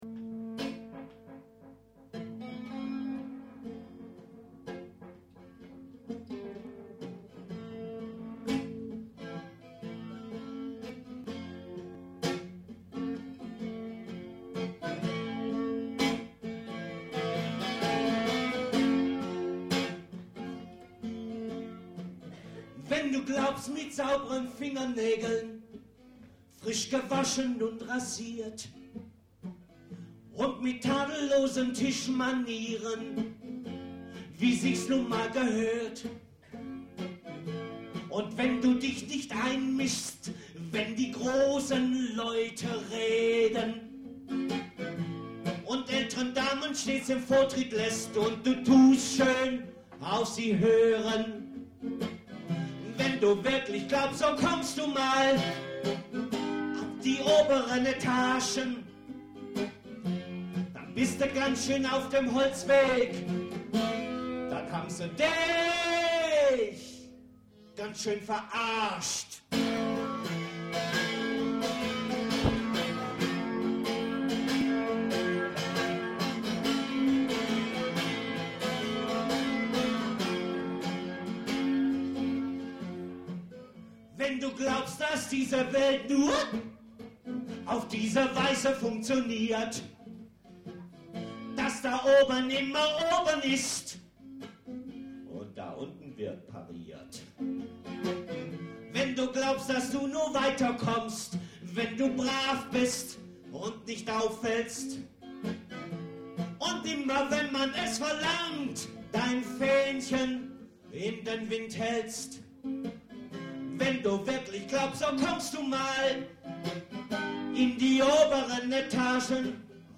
"LIVE"